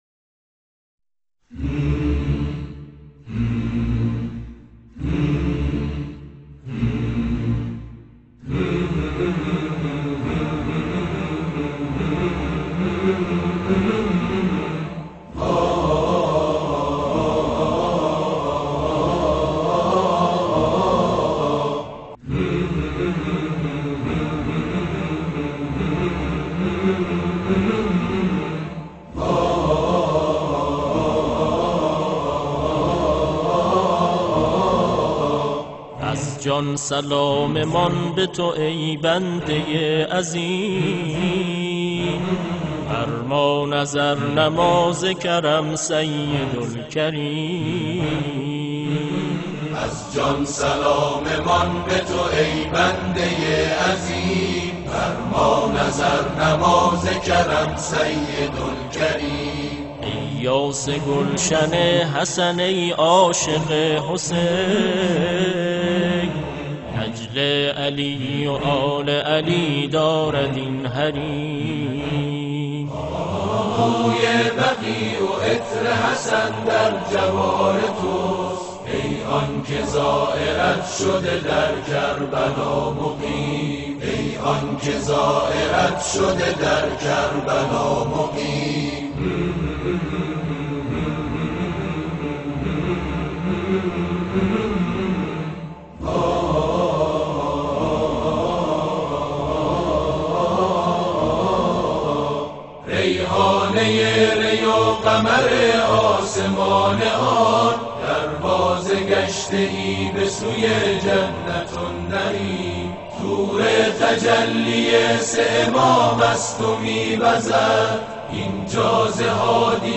اجرای تلویزیونی تواشیح